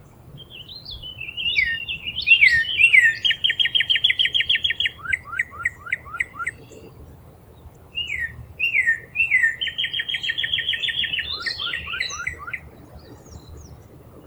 birds.wav